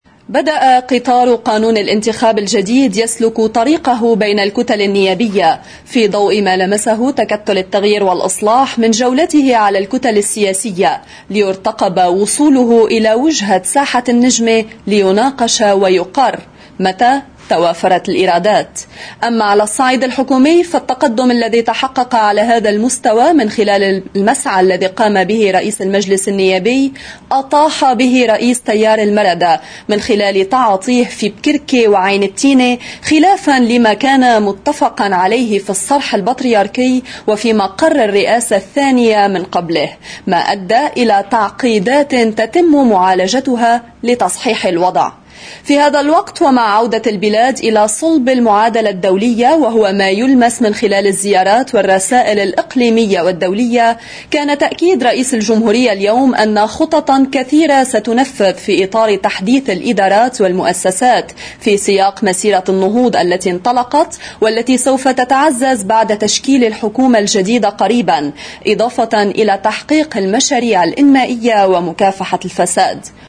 مقدمة نشرة أخبار OTV